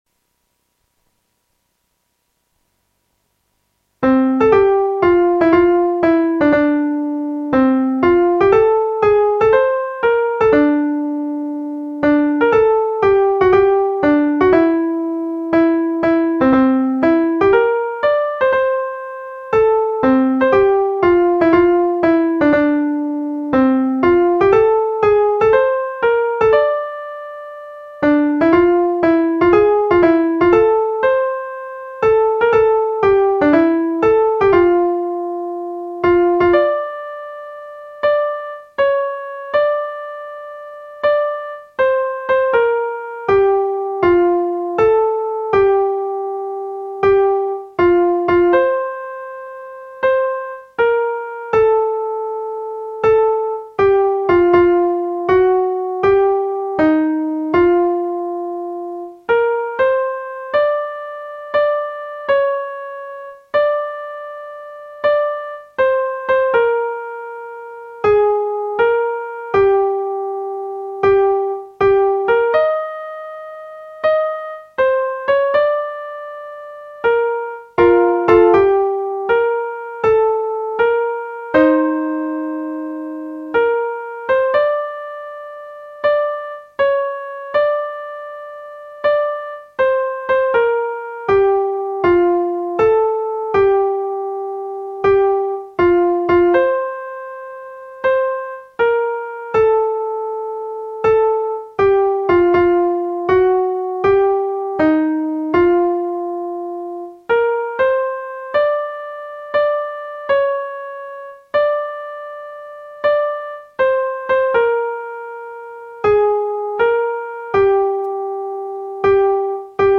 Aqui se encontra uma partitura mais simples, contendo apenas a linha melódica, que foi transcrita para meio eletrônico (.mp3, .mid, .pdf). Dessa transcrição, foi gravada, com auxílio de computador, essa linha melódica (deve-se imaginar o coro a quatro vozes, como sugere o original).
- Execução em computador da partitura acima.